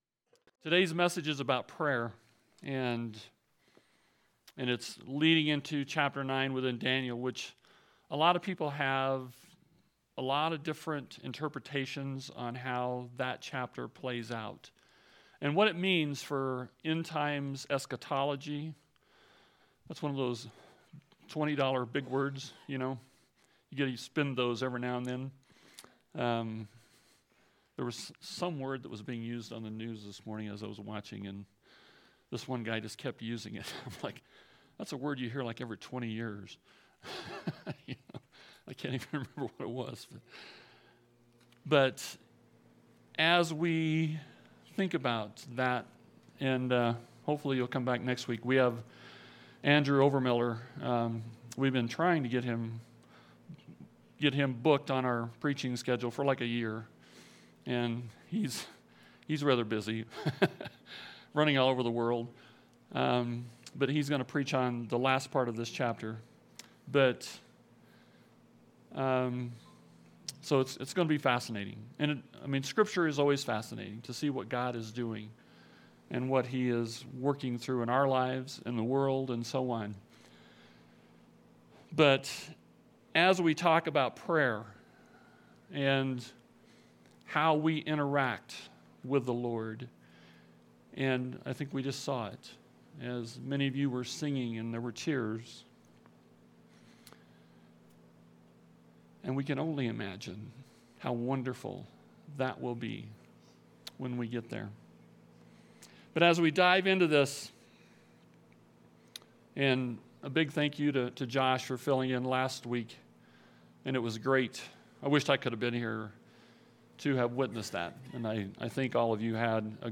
Test of Faith Message